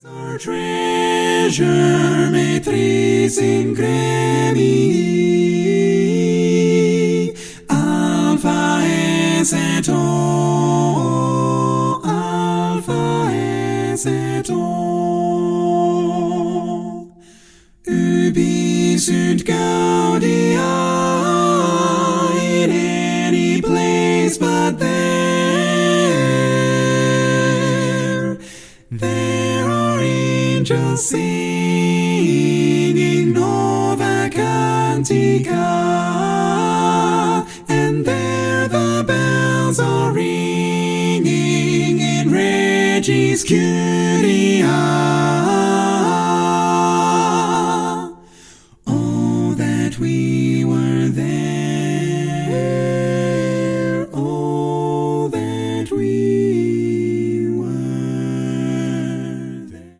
Full mix
Categories: Male , Mixed